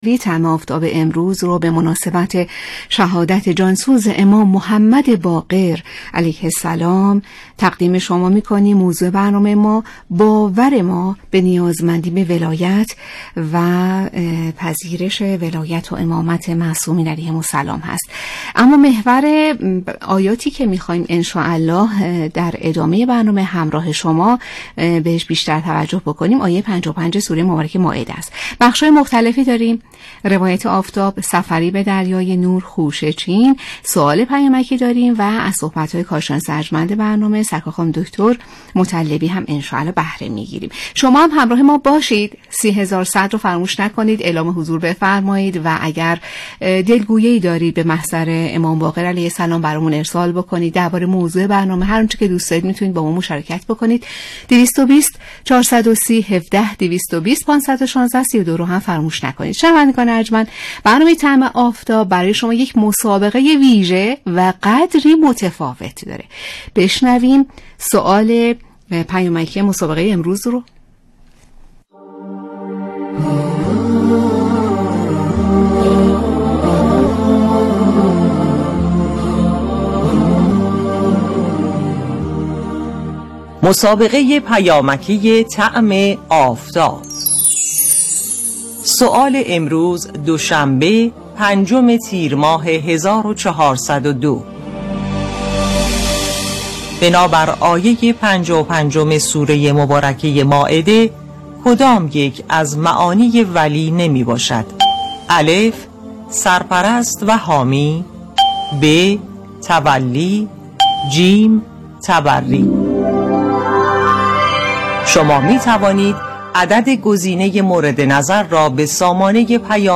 به گزارش ایکنا، طعم آفتاب ویژه برنامه ایام تابستان شبکه رادیویی قرآن است که با محوریت «سبک زندگی قرآنی» روز‌های شنبه تا پنجشنبه حوالی ساعت ۱۰ تا ۱۱ به صورت زنده روی آنتن می‌رود.